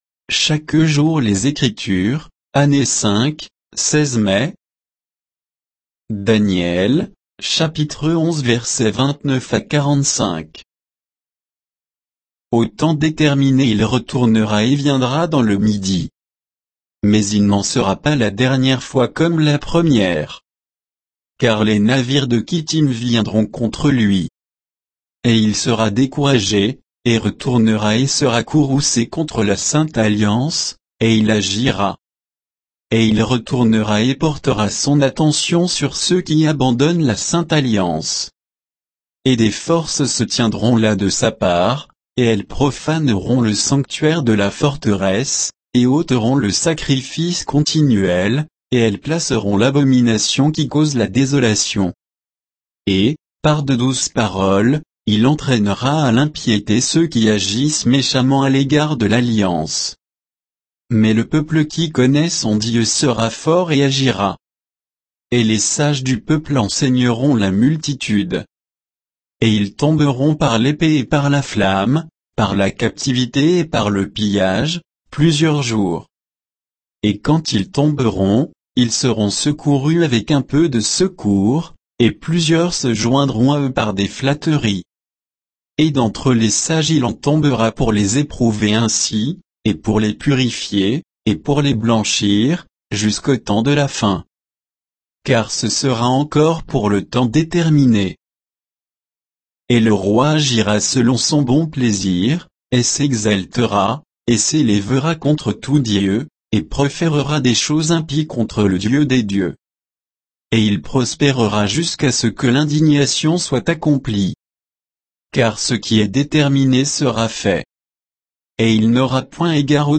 Méditation quoditienne de Chaque jour les Écritures sur Daniel 11, 29 à 45